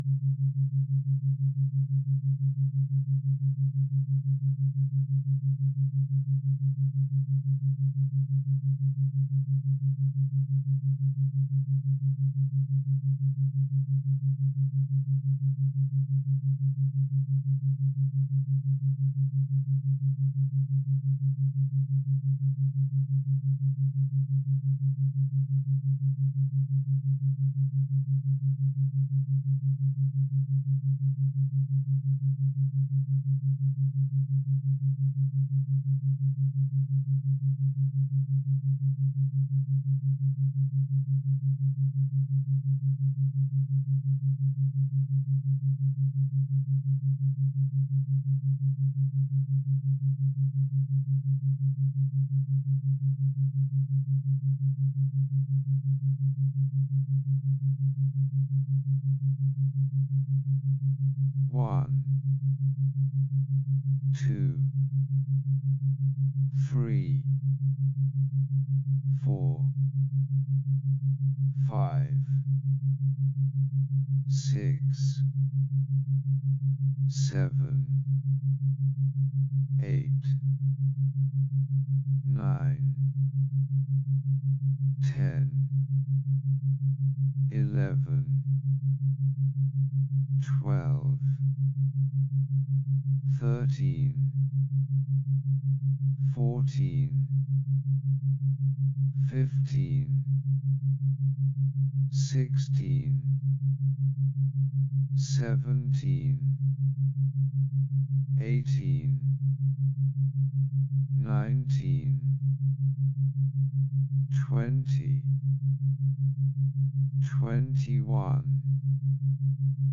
Confidence builder self-hypnosis audio.
confHYpnoSession.mp3